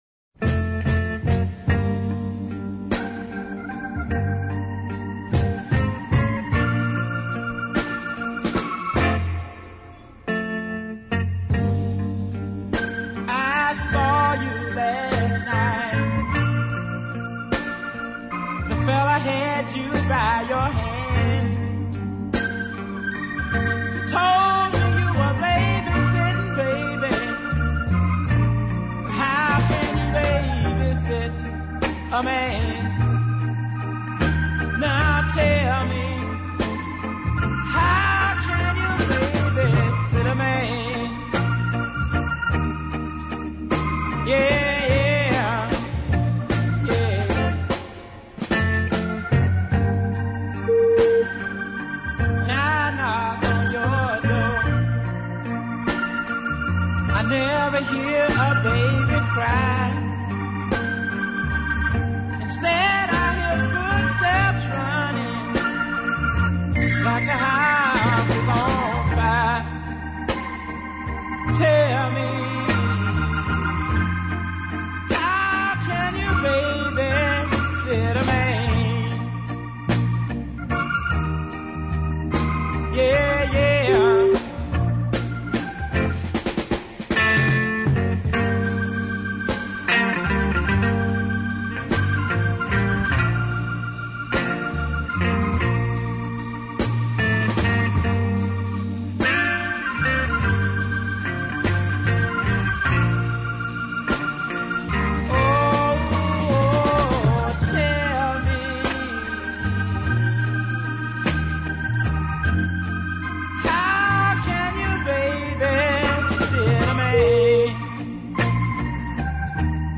gentle tenor vocals were well modulated
superby structured deep soul ballad
and the excellent organist behind him